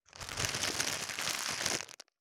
625コンビニ袋,ゴミ袋,スーパーの袋,袋,買い出しの音,ゴミ出しの音,袋を運ぶ音,
効果音